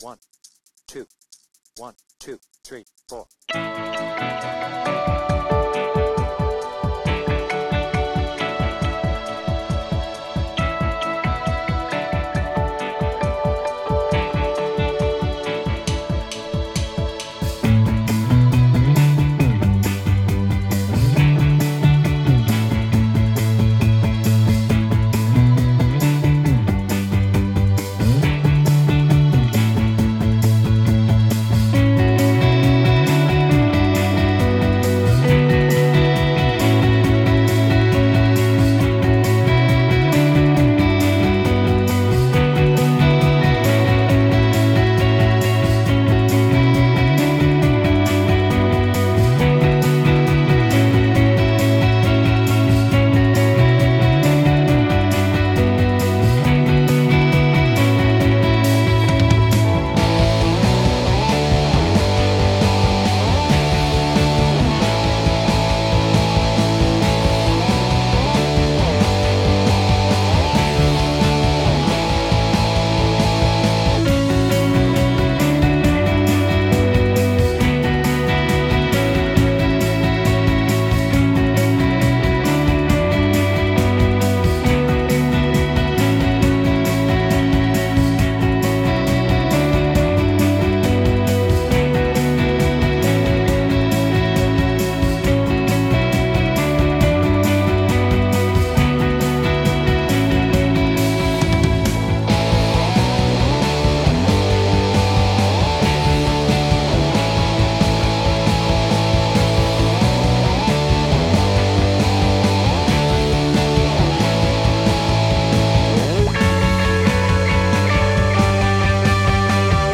BPM : 136
Without vocals